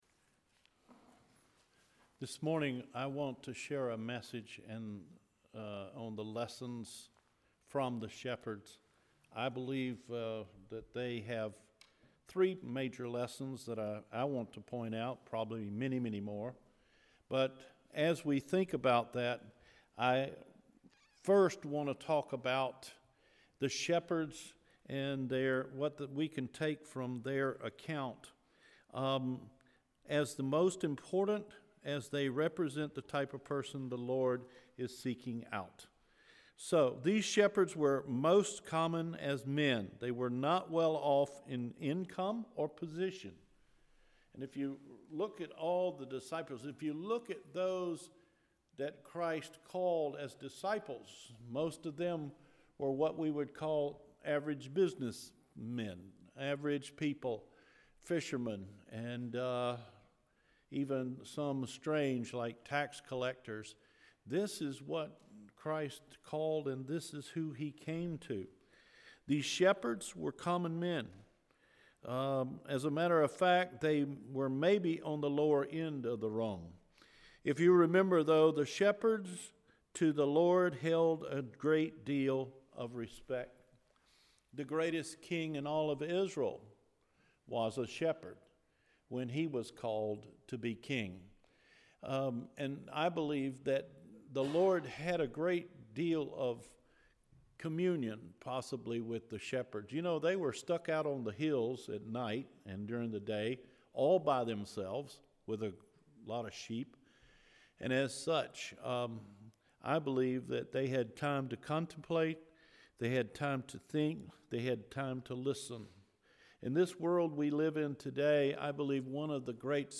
Who Were the Shepherds? – December 10 2017 Recorded Sermon